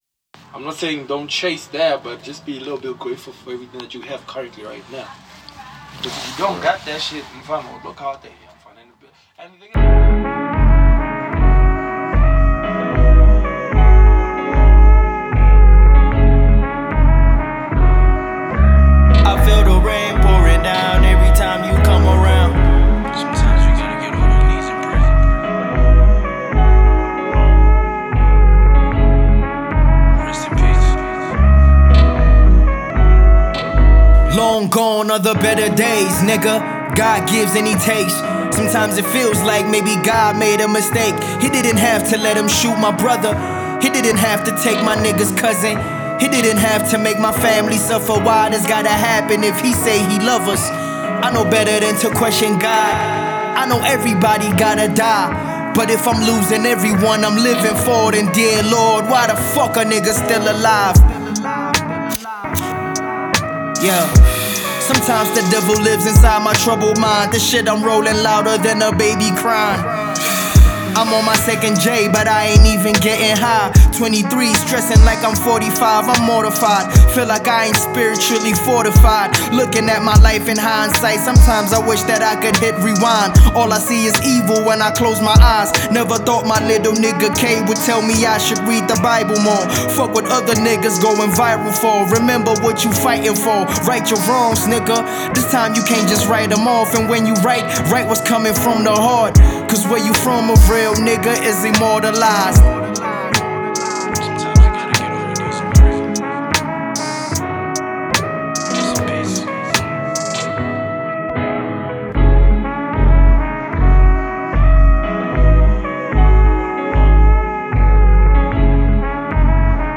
Genre: R&B/Soul/HipHop